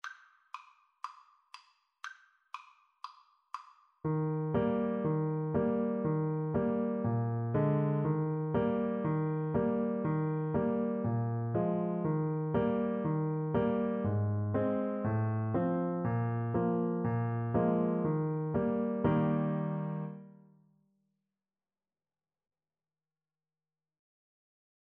Euphonium version
Bb3-Ab4
Euphonium  (View more Beginners Euphonium Music)